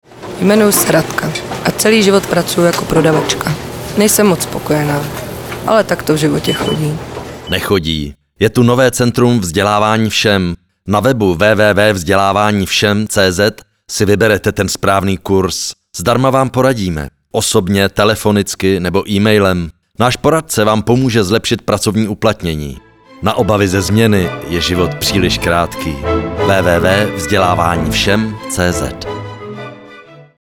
Ukázka - Vzdělávání všem (vrz. žena) Vzdělávání všem (vrz. žena)
Kategorie: Rádiové spoty
Druh: rádiový spot
vzdelani_vsem-zena.mp3